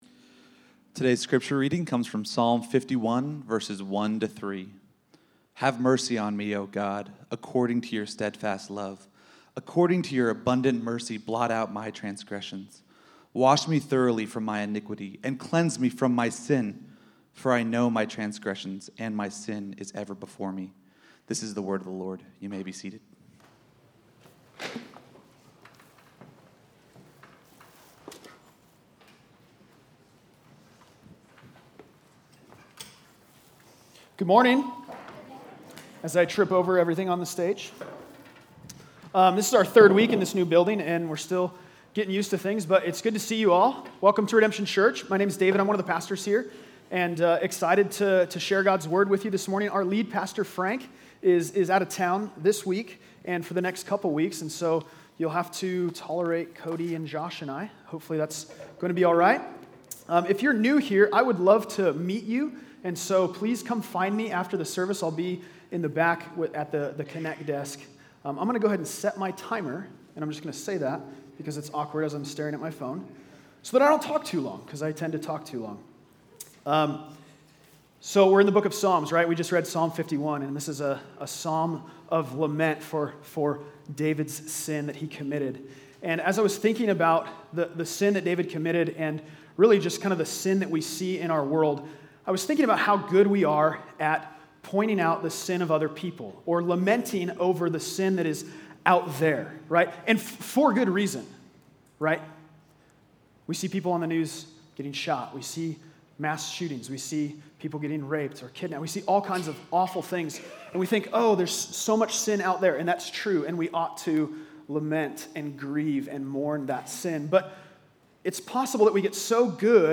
The Psalms: Psalm 51 - Redemption Arcadia Sermons | PodPa...